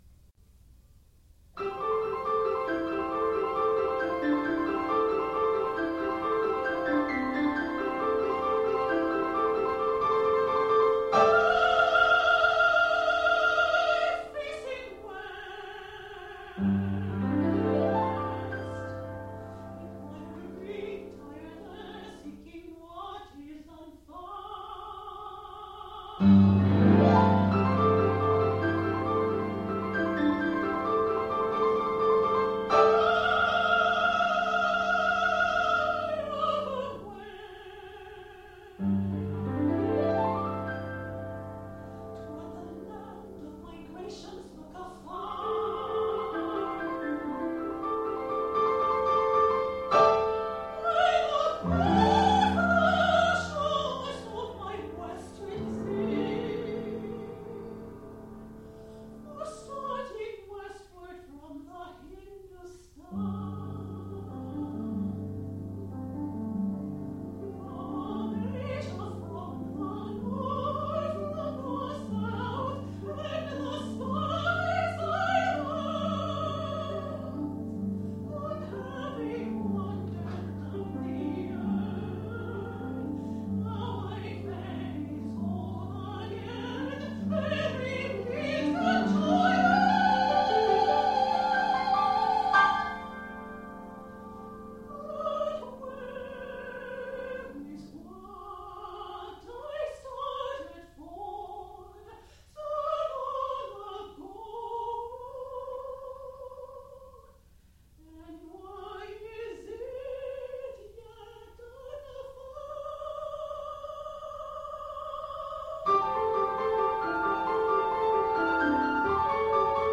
SONG CYCLES